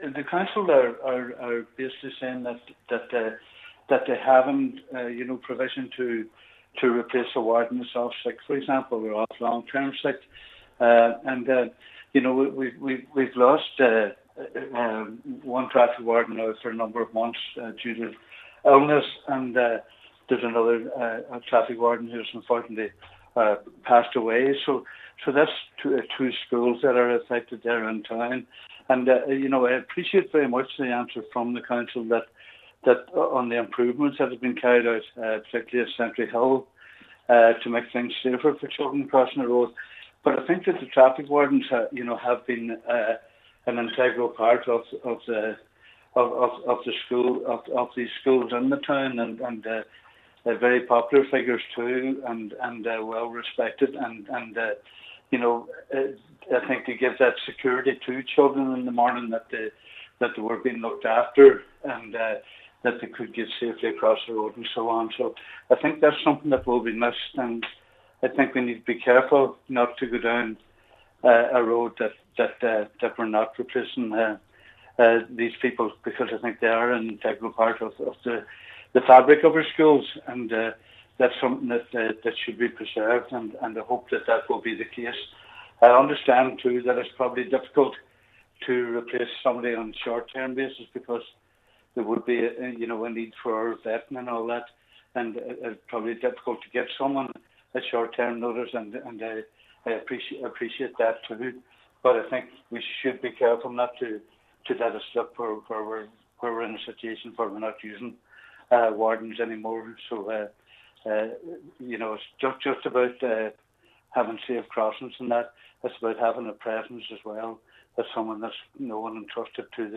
Cllr Kavanagh says there is a line to tread to ensure the human element is not lost in children’s road safety: